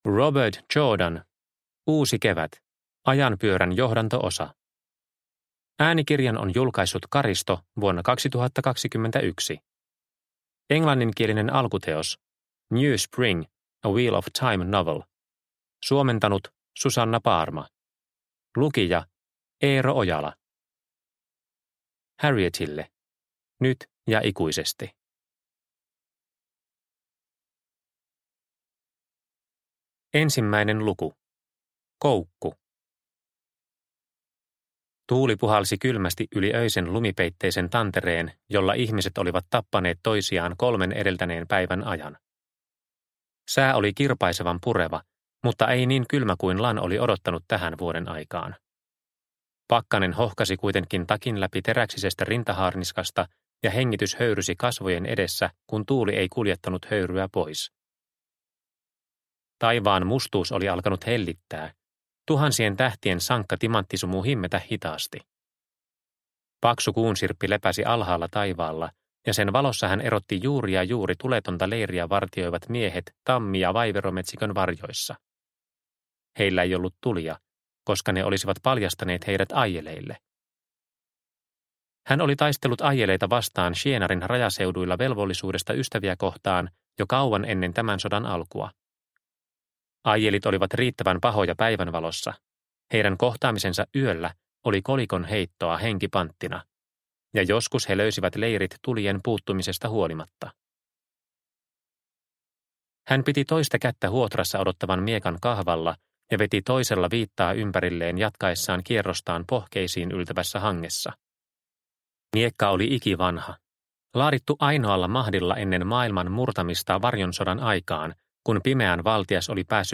Uusi Kevät – Ljudbok – Laddas ner